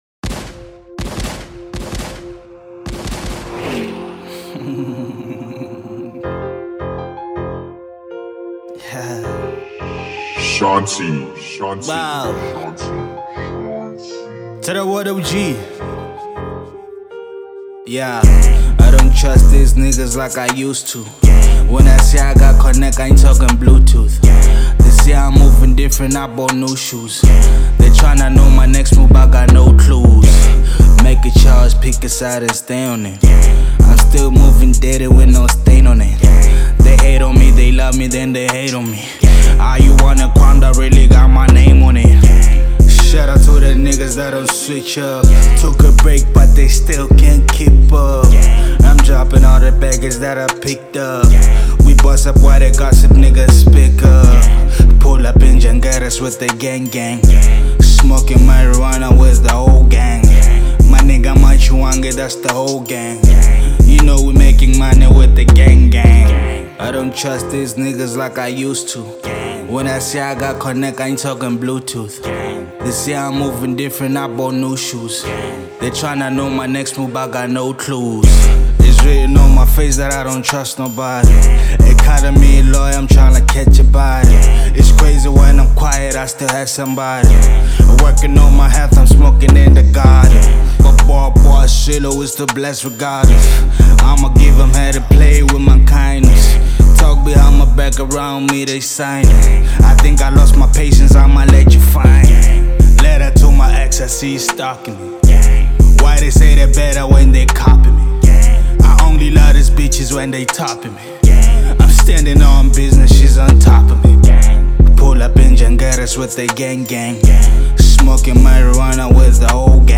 02:18 Genre : Trap Size